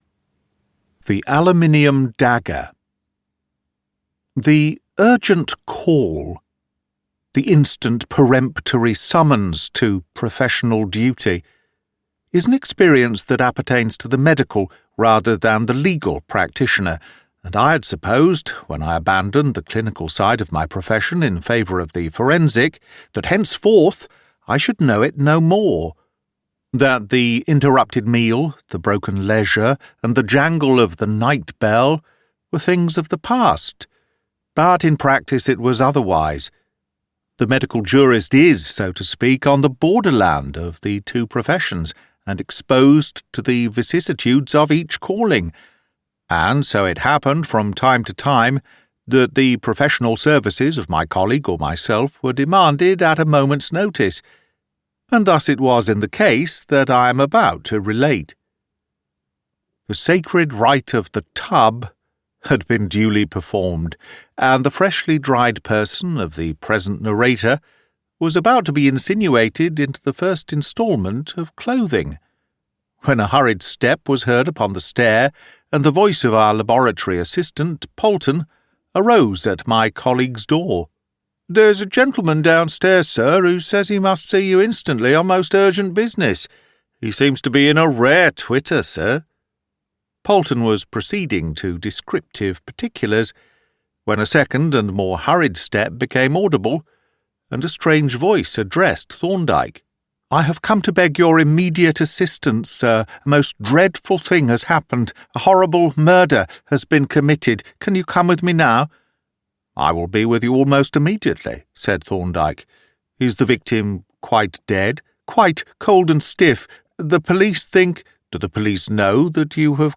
The Call of Cthulhu by H.P. Lovecraft - Horror Audiobook